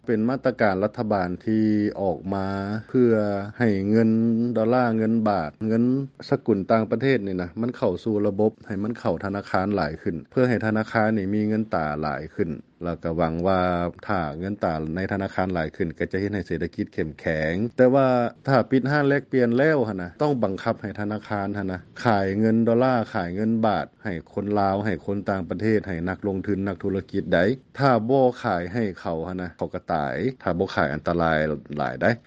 ສຽງປະຊາຊົນສະແດງຄວາມເປັນຫ່ວງໃນການແລກປ່ຽນເອົາເງິນຕາຕ່າງປະເທດຈາກທະນາຄານ